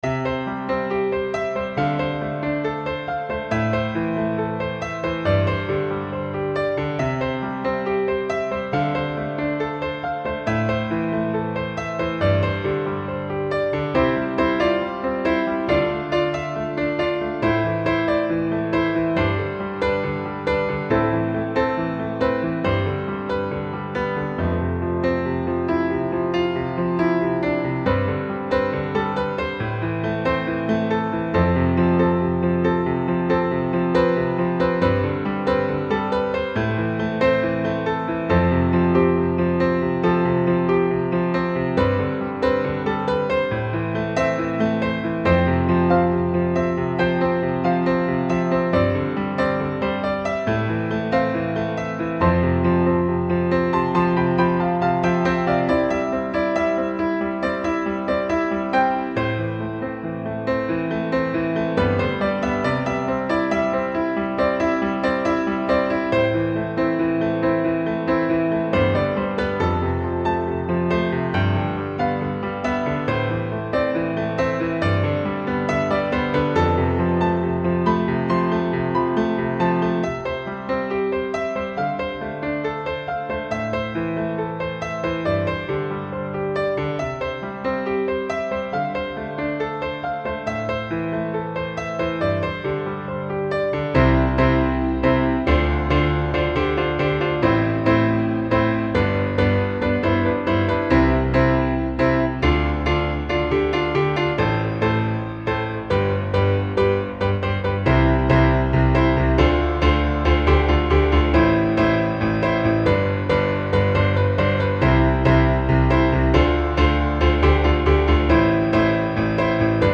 Эквализация фортепиано
Очень хочу услышать советов по поводу эквализации фортепиано при условии, что кроме него в треке нет никаких других инструментов.